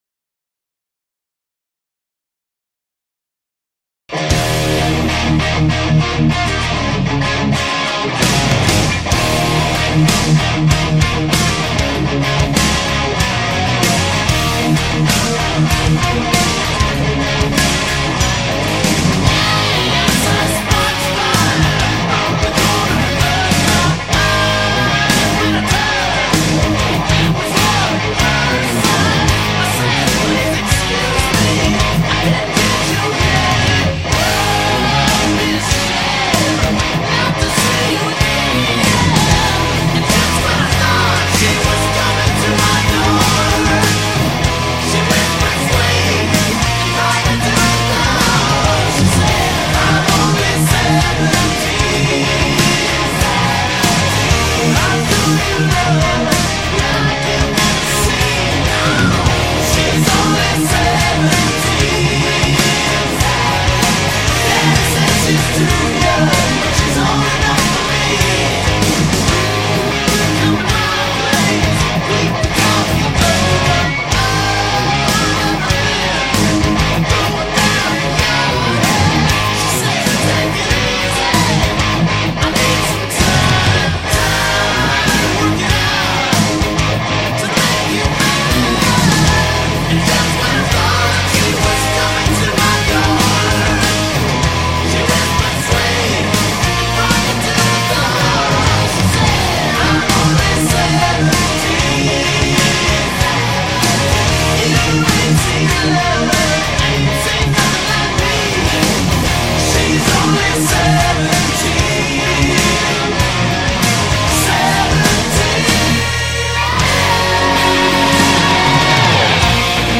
Music / Rock
guitar